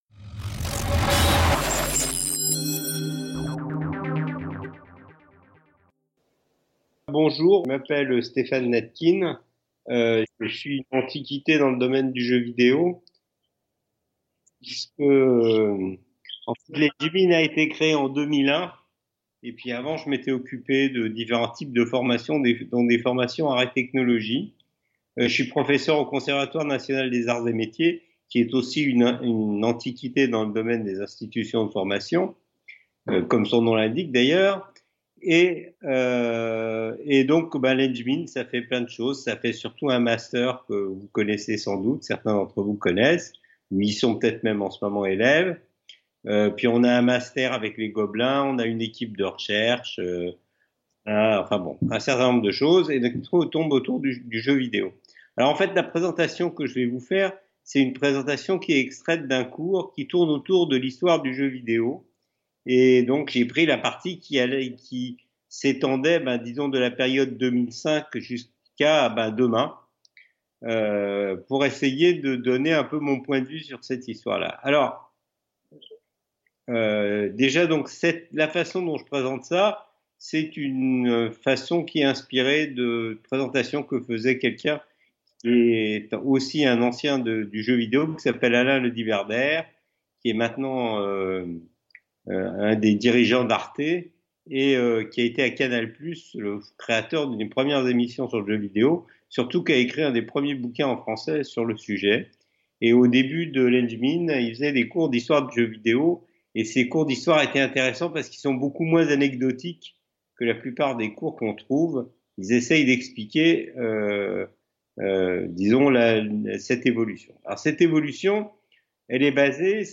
[#4] Atelier débat - L'univers du Jeu vidéo : une industrie en plein bouleversements.